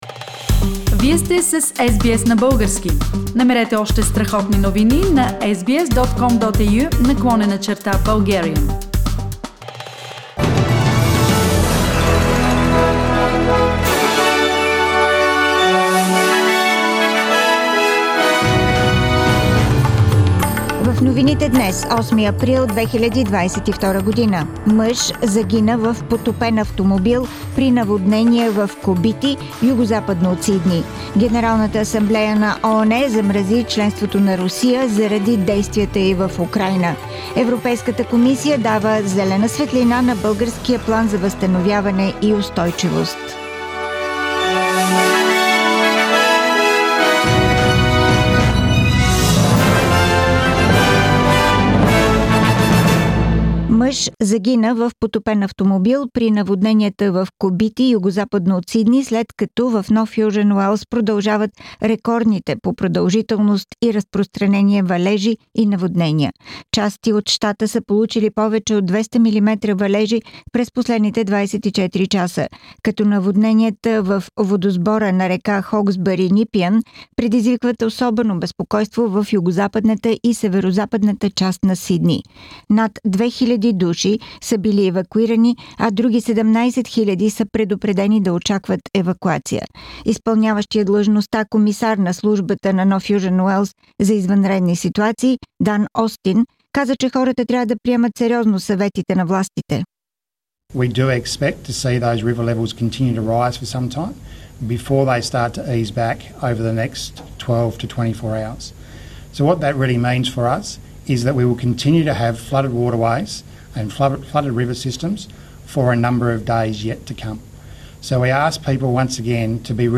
Weekly Bulgarian News – 8th April 2022